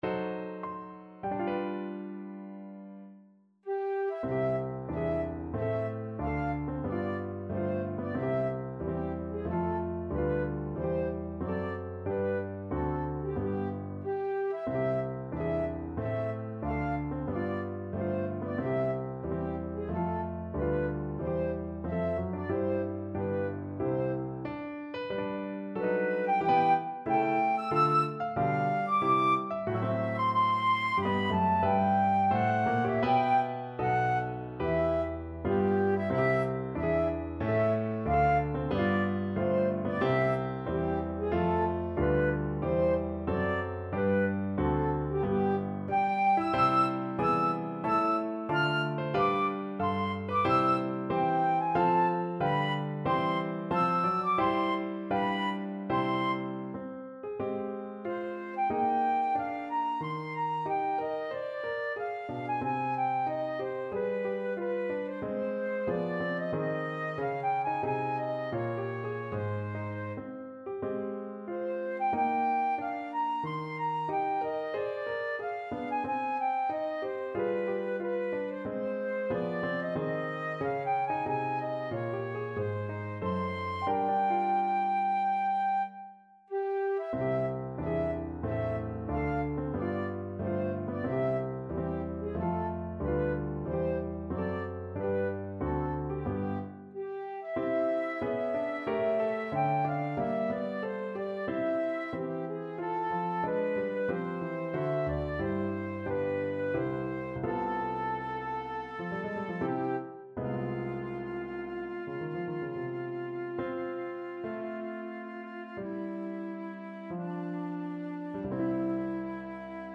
Flute version
C major (Sounding Pitch) (View more C major Music for Flute )
4/4 (View more 4/4 Music)
Classical (View more Classical Flute Music)